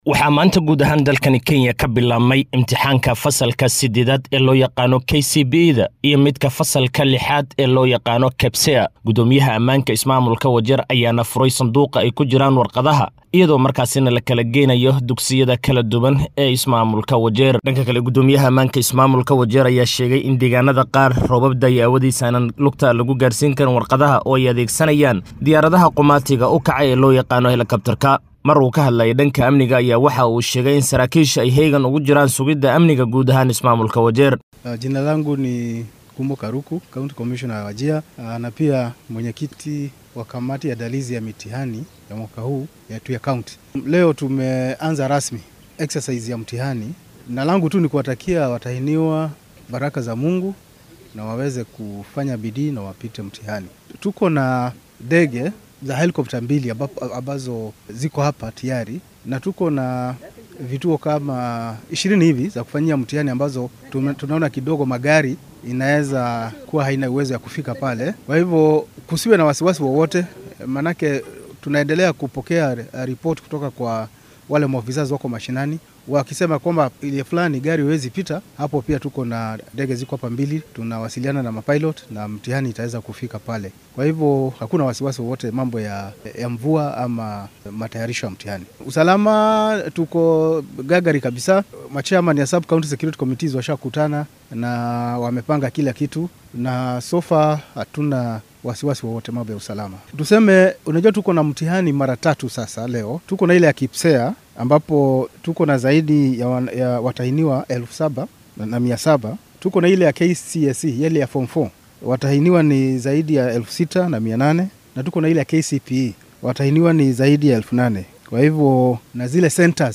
Wararka Kenya